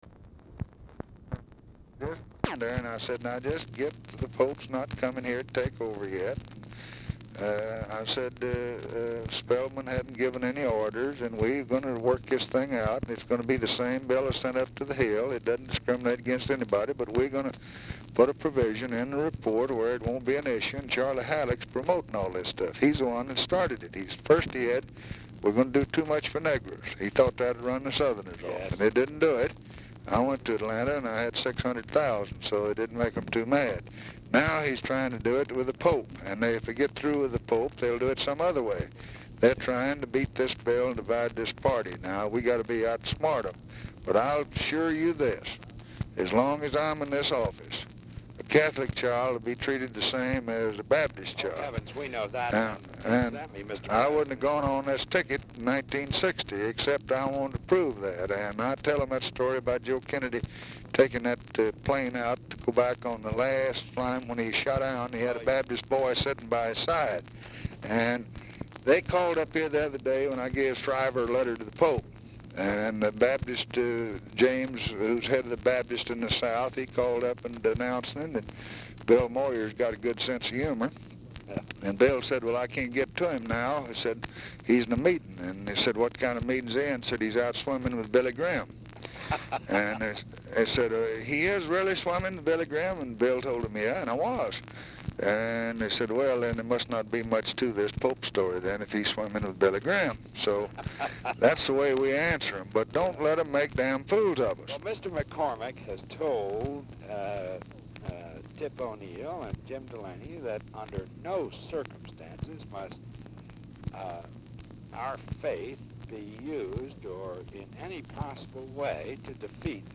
Oval Office or unknown location
CONTINUES FROM PREVIOUS RECORDING; THOMPSON, O'HARA ARE MEETING WITH MCCORMACK AT TIME OF CALL; SELECTED AS A HIGHLIGHT OF THE TELEPHONE CONVERSATION COLLECTION
Telephone conversation
Dictation belt